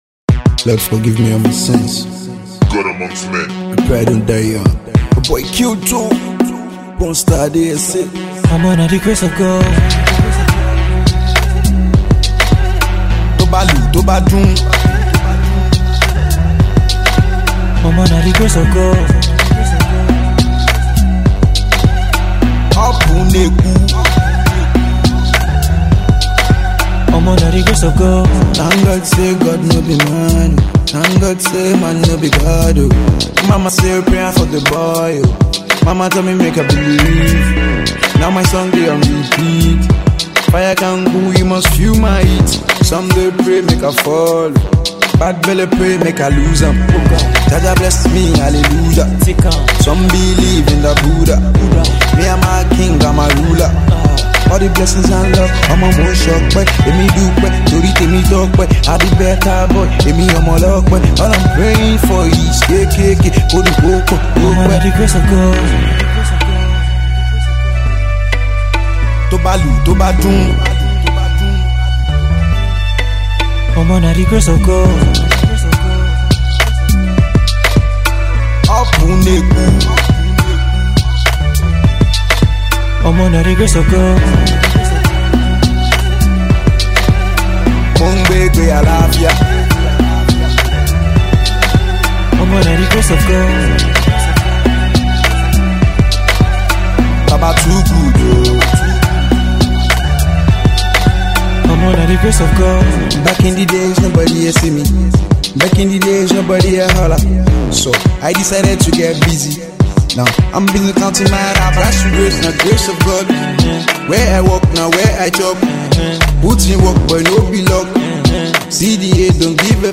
Alternative Pop
Afro-trance